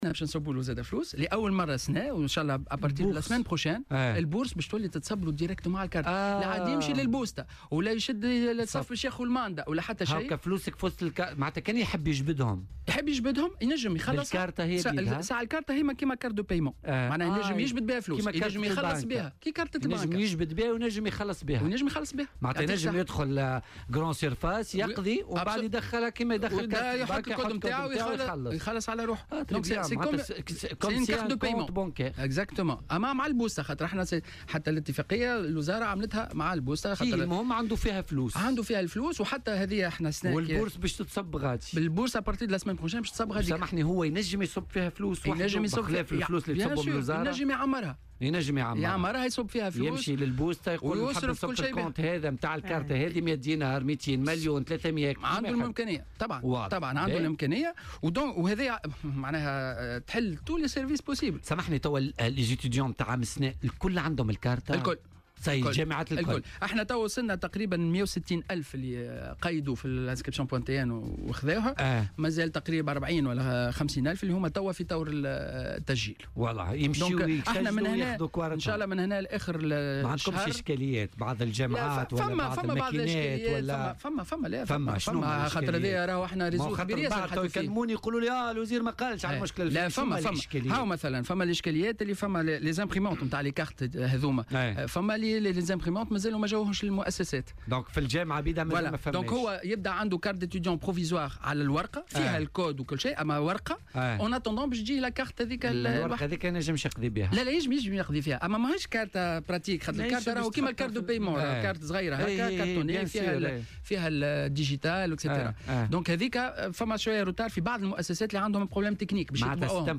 تحدّث وزير التعليم العالي والبحث العلمي، سليم خلبوس، في برنامج "بوليتيكا" على "الجوهرة أف أم" عن جديد الخدمات الجامعية.